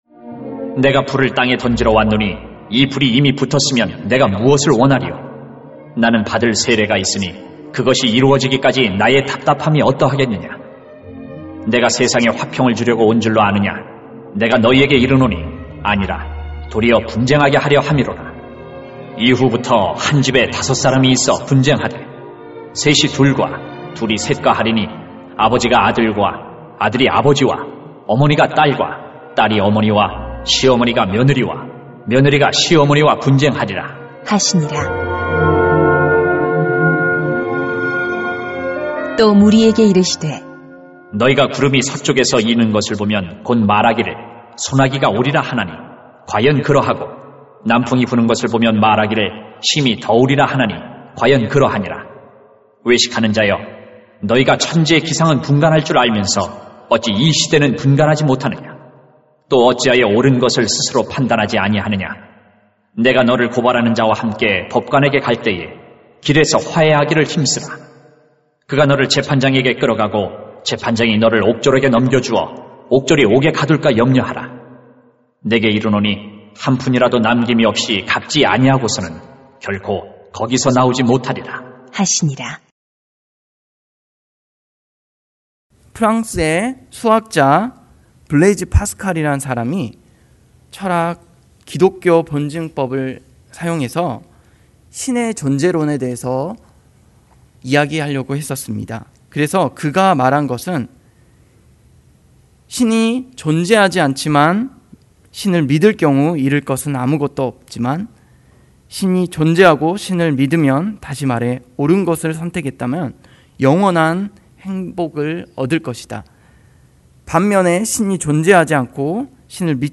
[눅 12:49-59] 불을 던지러 왔노라 > 새벽기도회 | 전주제자교회